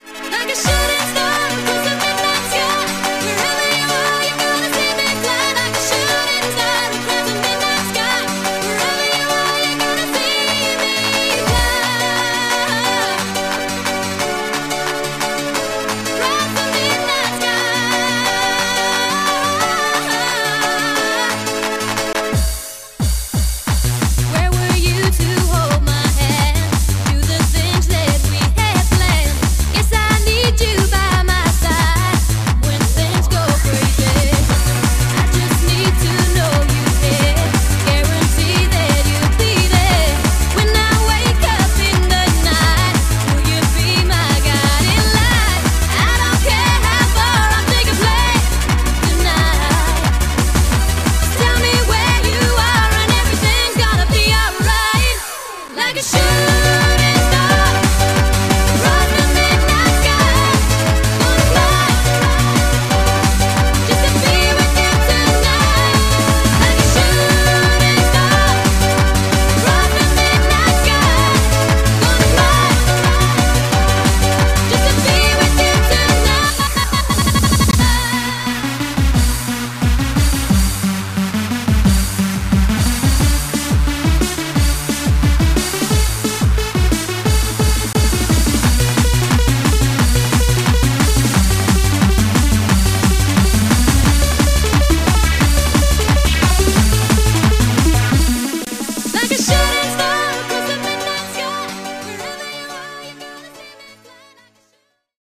BPM176-177
Audio QualityCut From Video